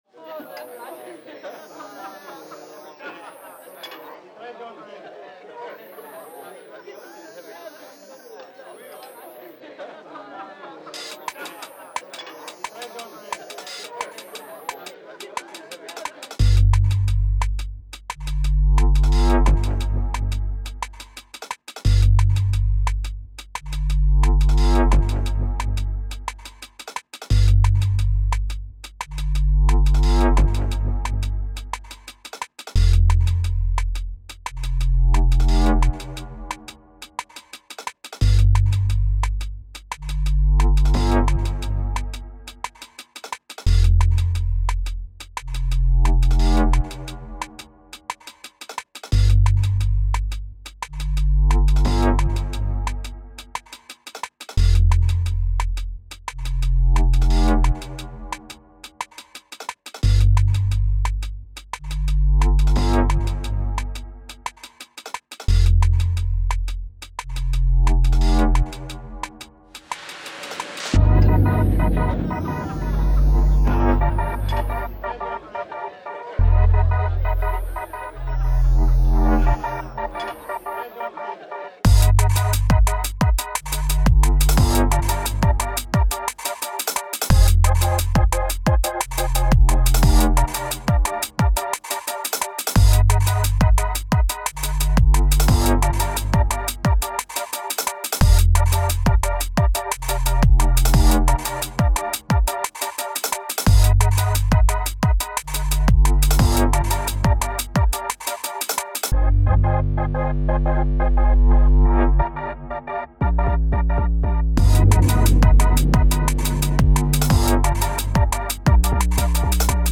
Podcast Theme (FULL VERSION)